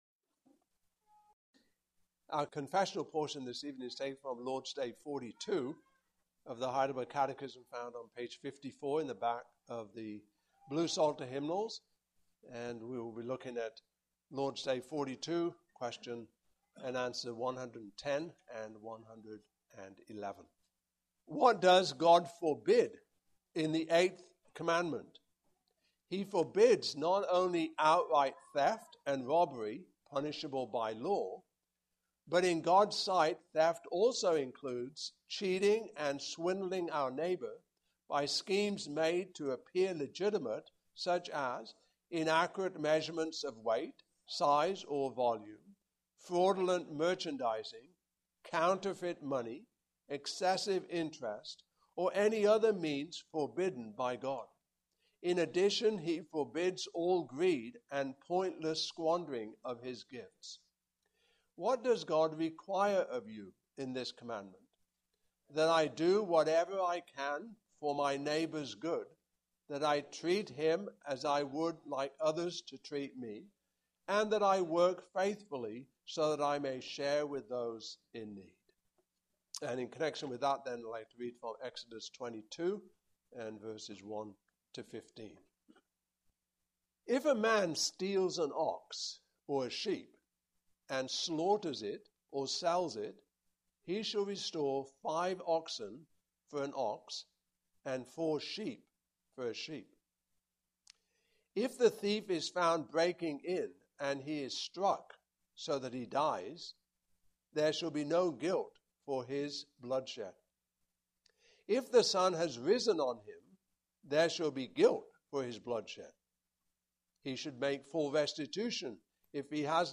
Passage: Exodus 22:1-15 Service Type: Evening Service